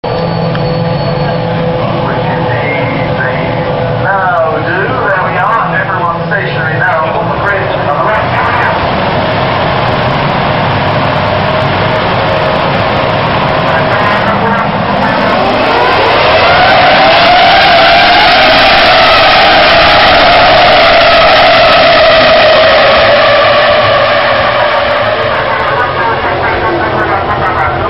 この時のエグゾーストは物凄かったです！！
折角なのでスタート時の音声をMP3にしてみましたので、雰囲気だけでも
音量注意です！！